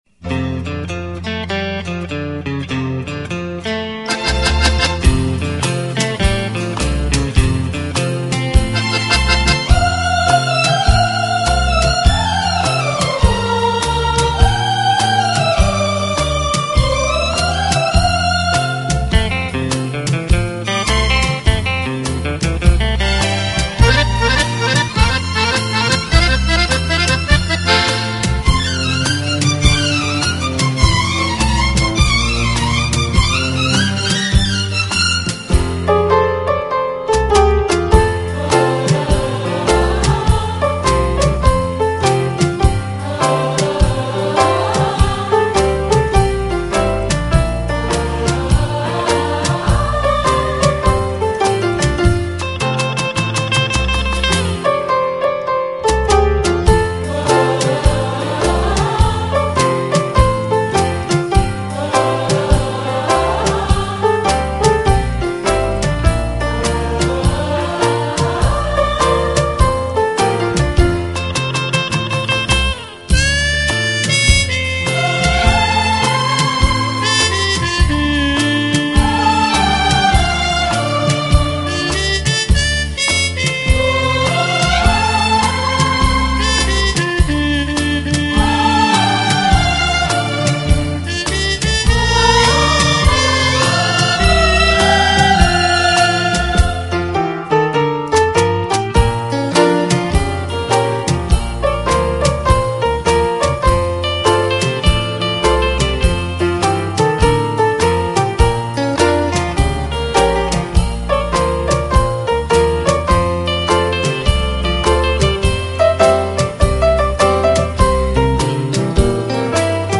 Instrumental Songs > Old Bollywood